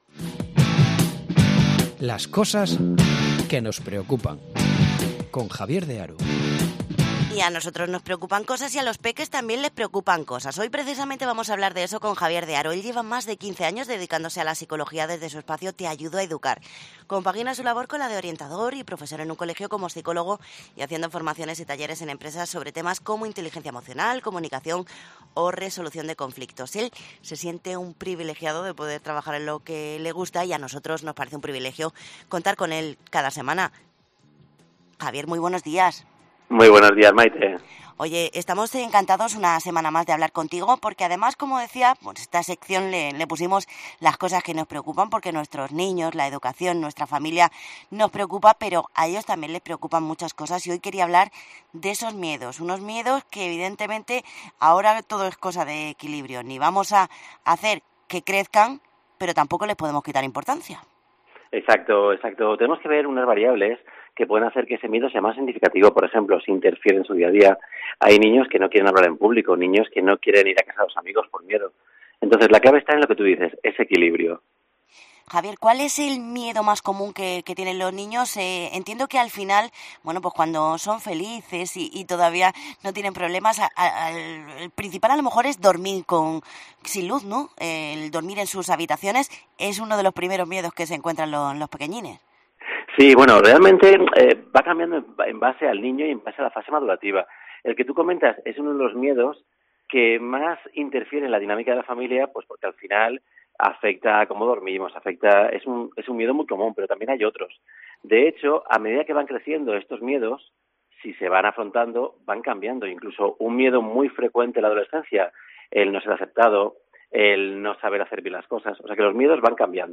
educador, nos da algunos trucos para tratar los miedos de los niños y los adolescentes